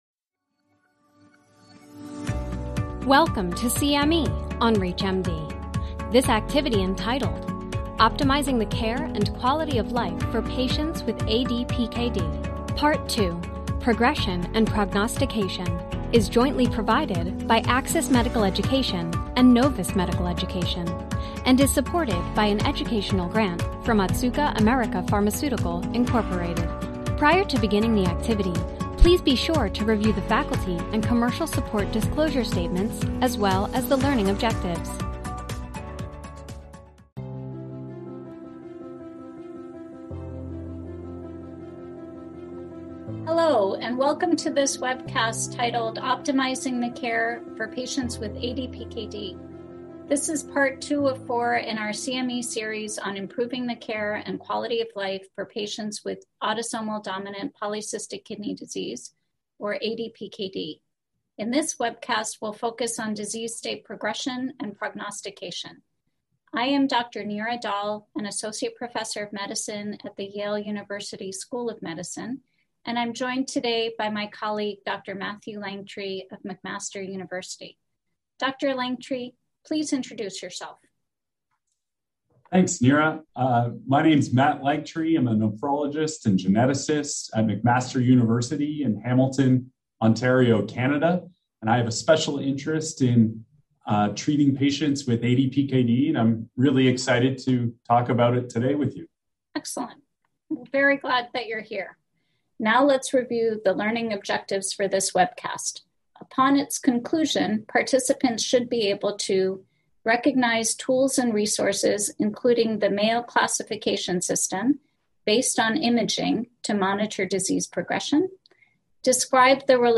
This Expert Exchange webcast aims to improve clinicians’ ability to properly monitor disease progression and make treatment decisions based on prognostic indicators.